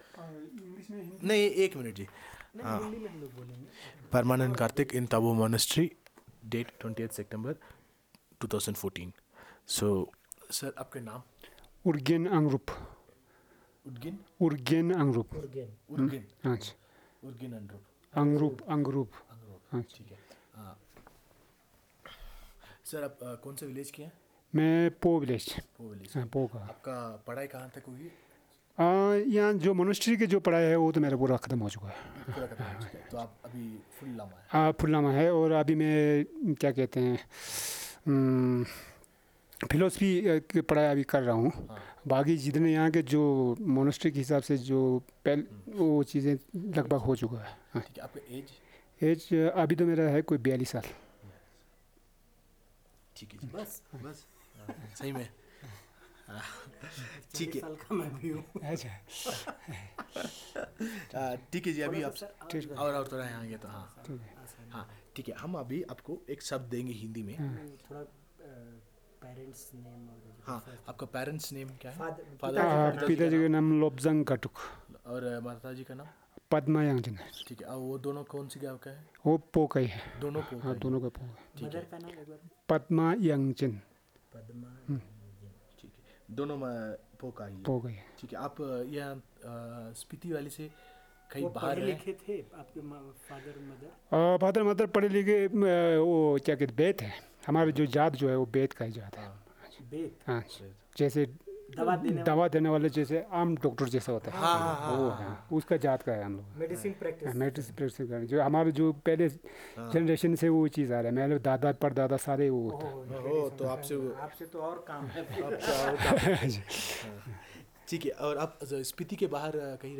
Elicitation of words about Pronouns, Conjuctions, Animals, Adjectives, Body Parts, and Fire related.
NotesThis is an elicitation of words about Pronouns, Conjuctions, Animals, Adjectives, Body Parts, and Fire related.